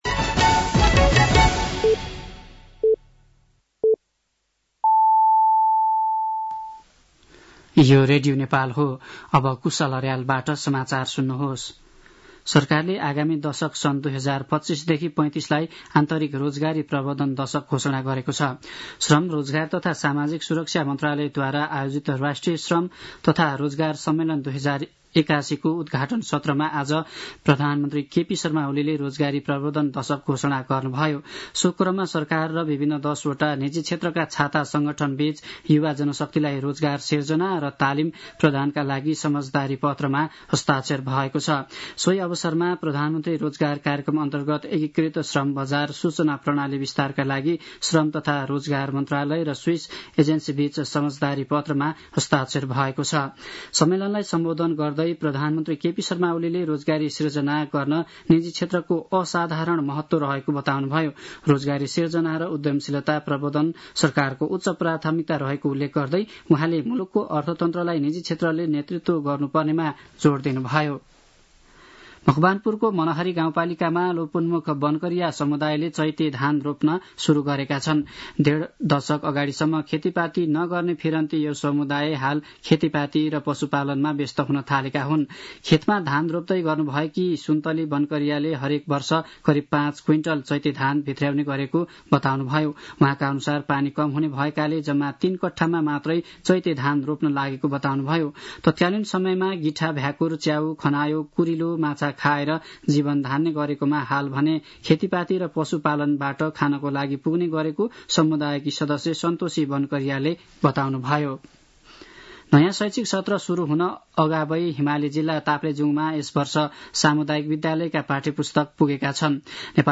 साँझ ५ बजेको नेपाली समाचार : २७ फागुन , २०८१
5-pm-nepali-news-11-26.mp3